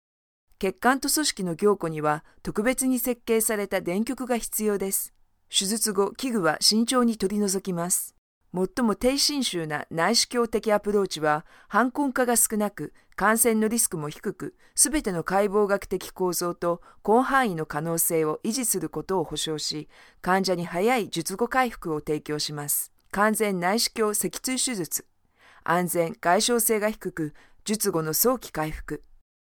特点：大气浑厚 稳重磁性 激情力度 成熟厚重
风格:浑厚配音